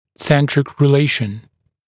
[‘sentrɪk rɪ’leɪʃn][‘сэнтрик ри’лэйшн]центральное соотношение (ЦС)